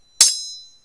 sword_clash.10.ogg